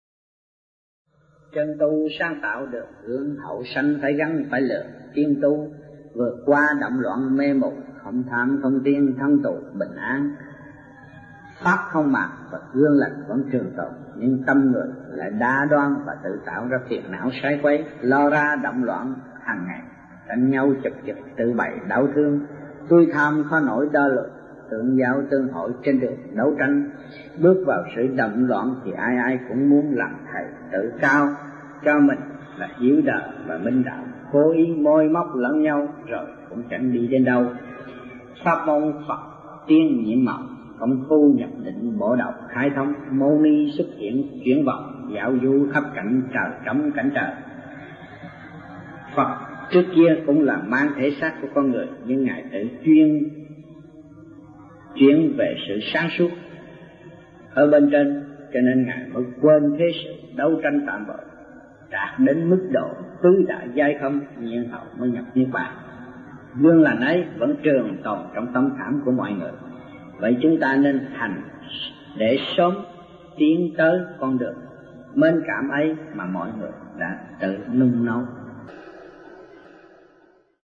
Trong dịp : Sinh hoạt thiền đường